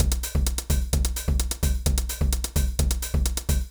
INT Beat - Mix 8.wav